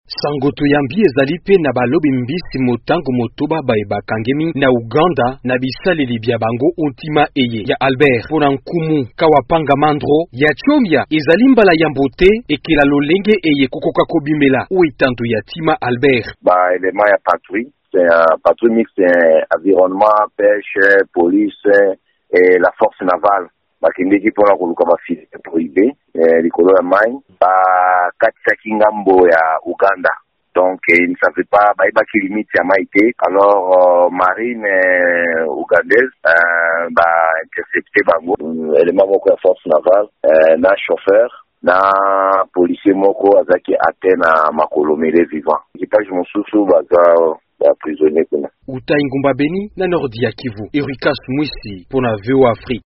Bana ba RDC babale bakufi, babale bazoki mpe mitano bakangami na mampinga ya Ouganda na lac Albert ntango bakatisaki ndelo na kozanga koyeba. Sango